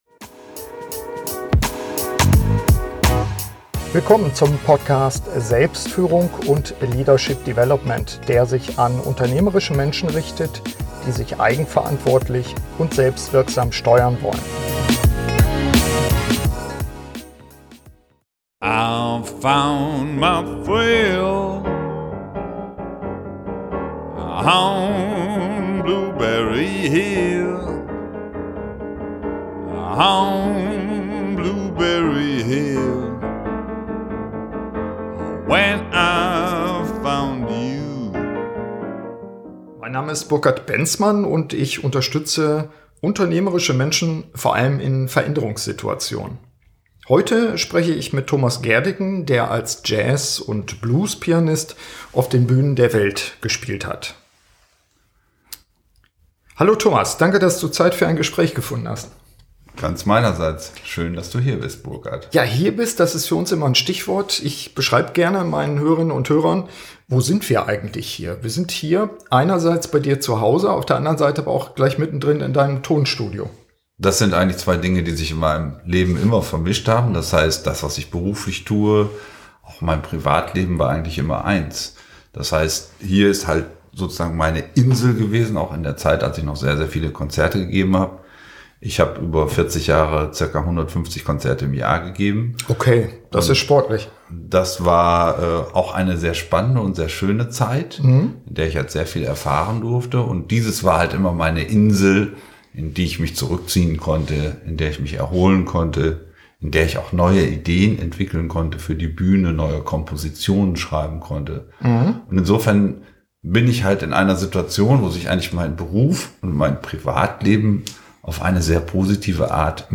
SF106 Führung mit der eigenen Stimme - Interview